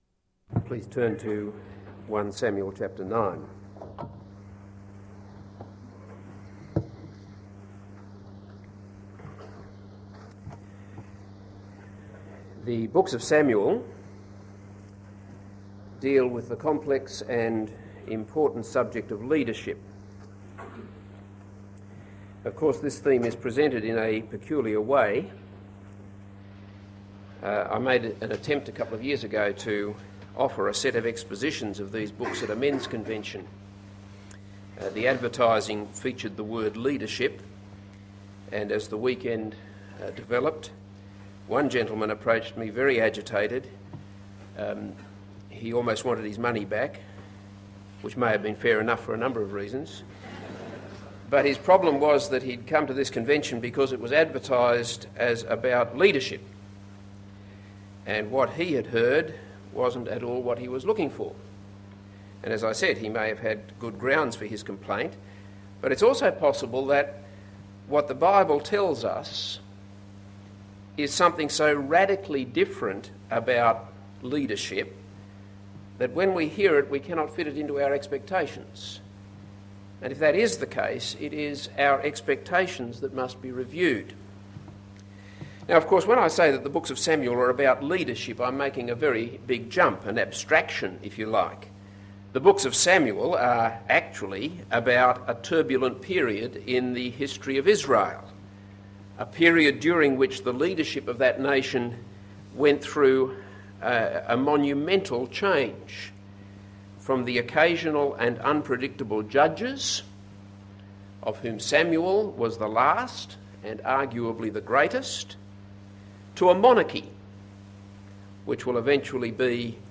This is a sermon on 1 Samuel 9.